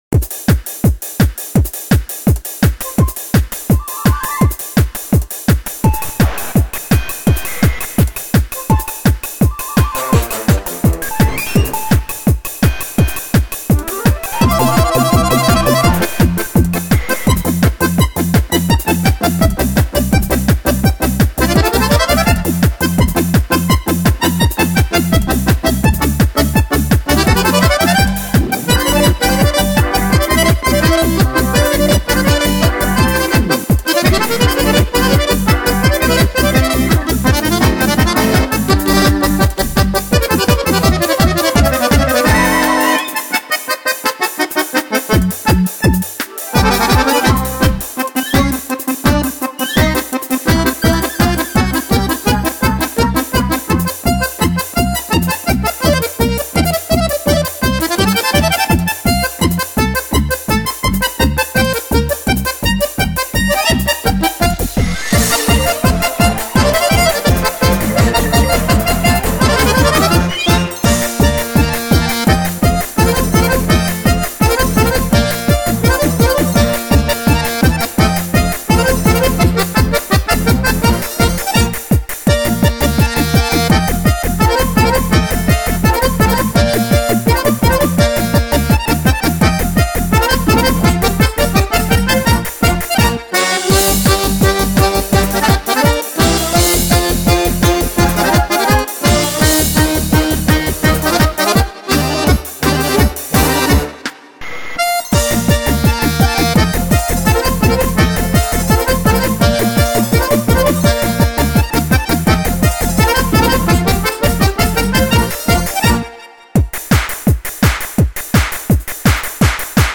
свой неповторимый стиль виртуозной игры на двух баянах